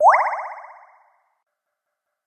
ぴちょん！水面にしずくが落ちる音。